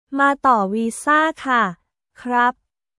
マー・トー・ウィーザー・カ／クラップ